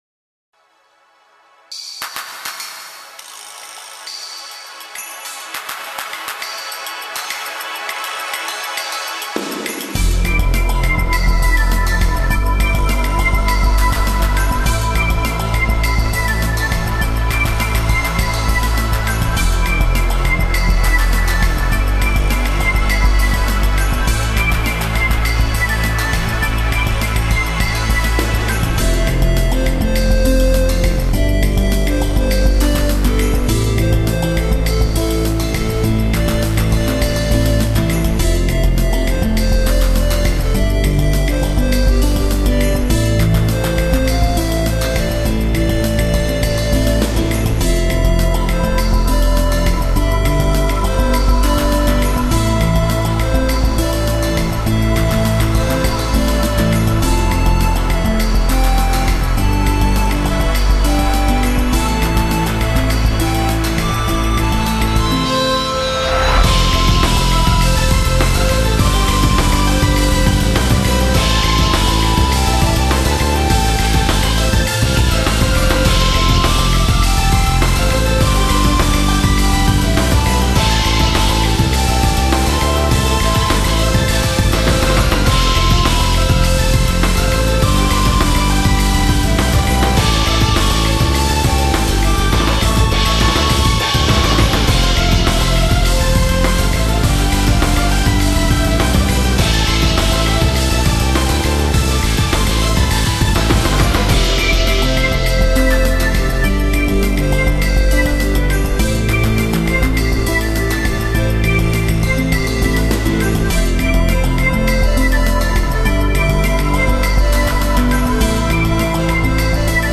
終始スリーコード展開も見ものだぞ（ぇ）。